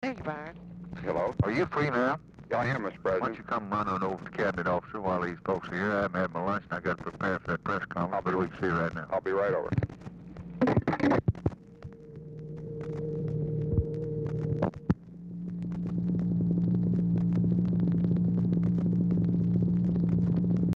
Telephone conversation # 4330, sound recording, LBJ and ROBERT MCNAMARA, 7/24/1964, 1:30PM?
Format Dictation belt
Location Of Speaker 1 Oval Office or unknown location
Specific Item Type Telephone conversation